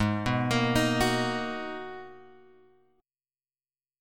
G#m9 chord {4 2 x 3 4 2} chord